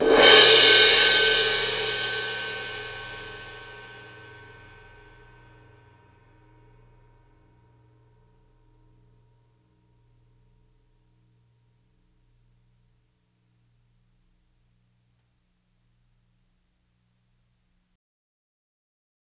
c-sus_cymbal-crash_with_flam.wav